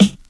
Hip hop snare drum Free sound effects and audio clips
• 00's Dry Hip-Hop Snare One Shot G Key 322.wav
Royality free snare drum sound tuned to the G note. Loudest frequency: 873Hz